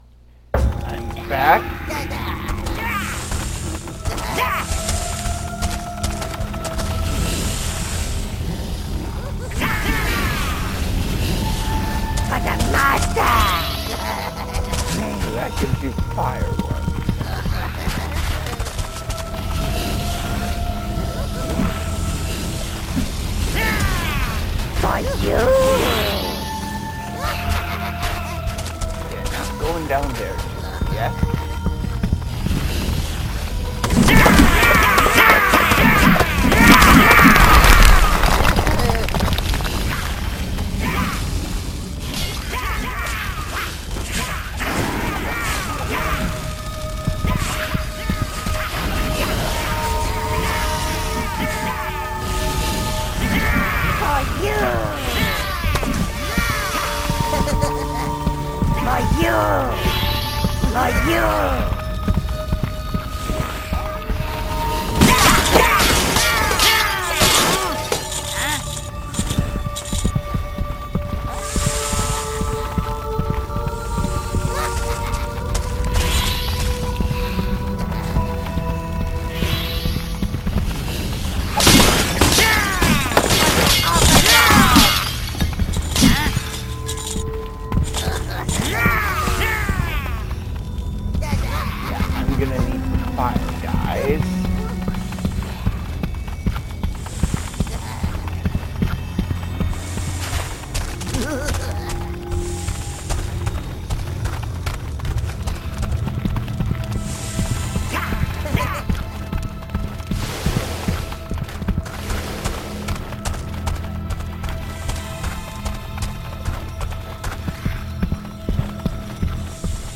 I play Overlord with commentary